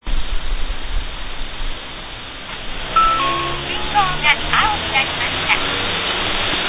この音ファイルは南北方向の歩行者用信号が青になったときの音声案内です。浜松名鉄ホテル前の信号交差点の東側の南北方向の歩行者用信号が青になったときの音声案内の声は男声ですが、この声はザザシティ中央館・西館の間の信号交差点の南北方向の歩行者用信号と同じく女声です。